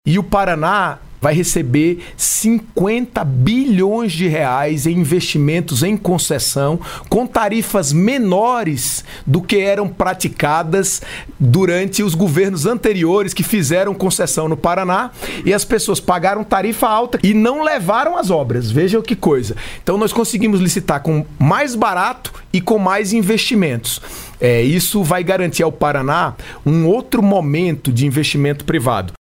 SONORA-MINISTRO-TRANSPORTES-02-CS.mp3